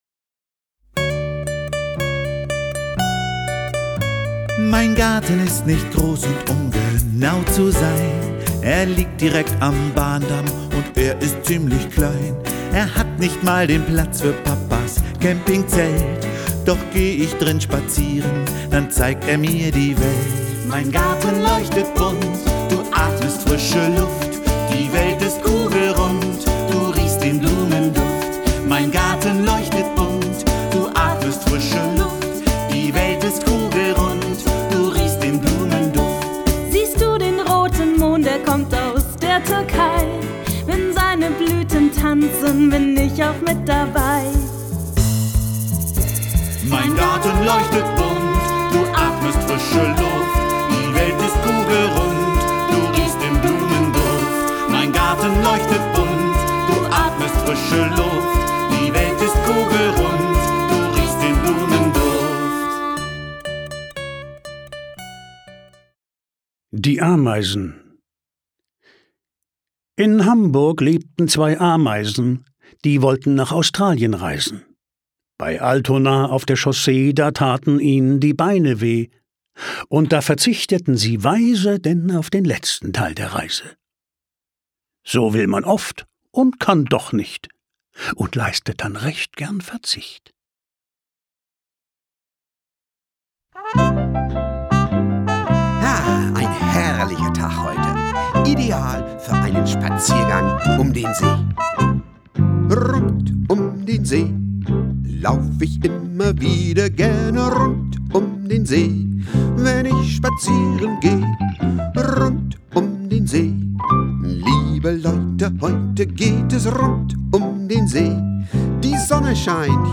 Die schönsten Lieder, Gedichte, Märchen und Geschichten